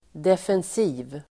Uttal: [defens'i:v (el. d'ef:-)]